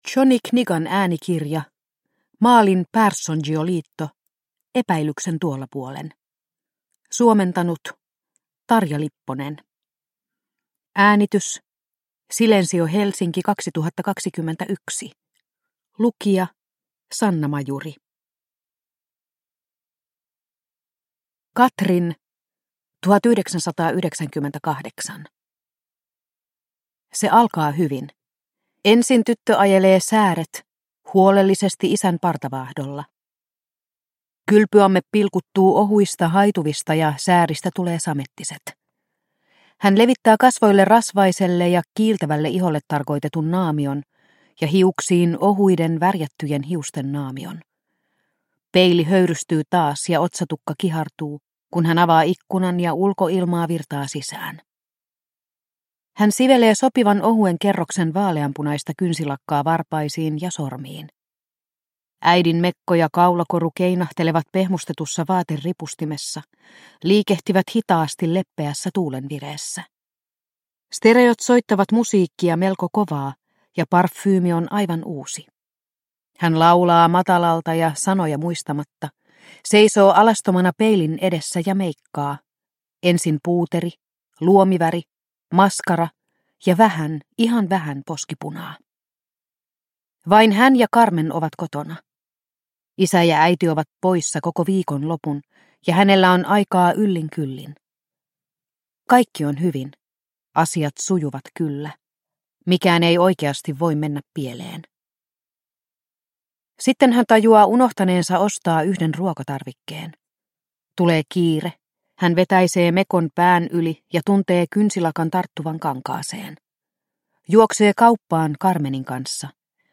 Epäilyksen tuolla puolen – Ljudbok – Laddas ner